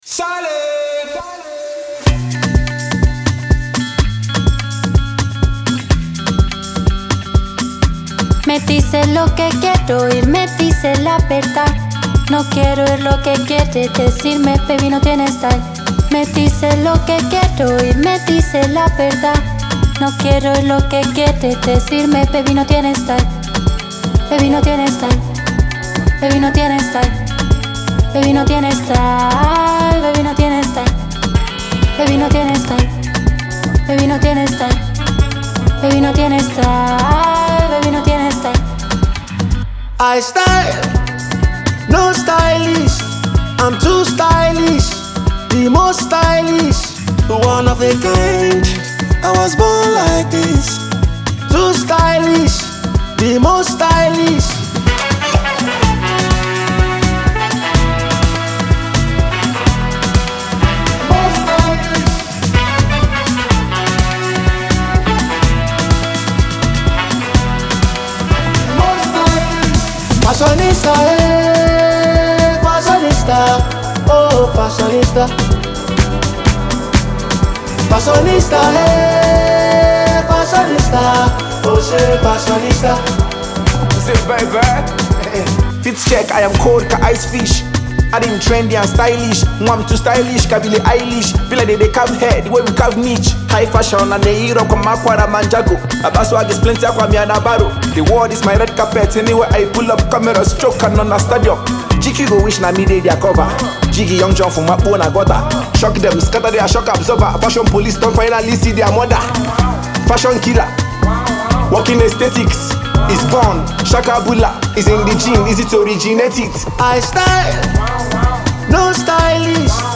Prolific indigenous Nigerian Rapper